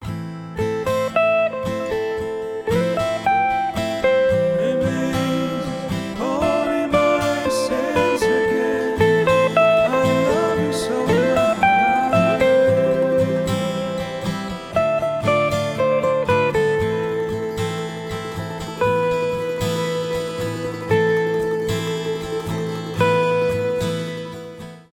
душевные
инструментальные , гитара